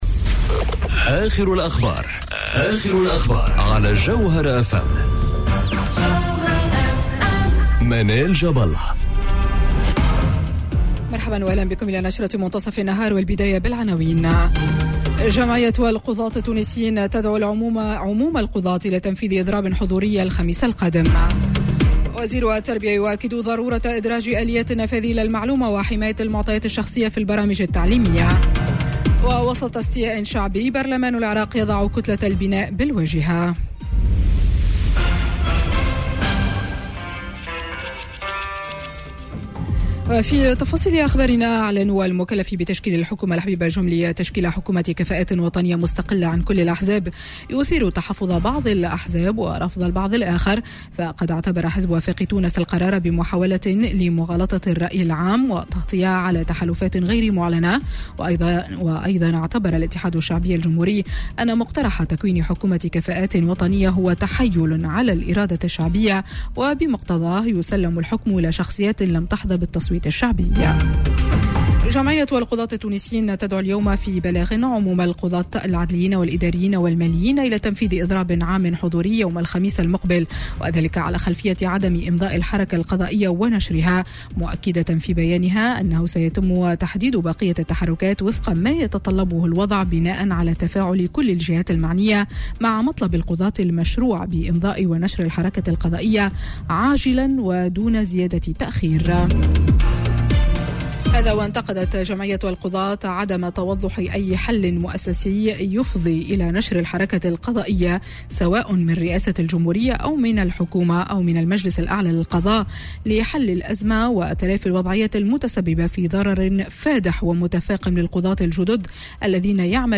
نشرة أخبار منتصف النهار ليوم الثلاثاء 24 ديسمبر 2019